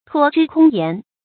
托之空言 注音： ㄊㄨㄛ ㄓㄧ ㄎㄨㄙ ㄧㄢˊ 讀音讀法： 意思解釋： 指寄托所懷于文詞議論。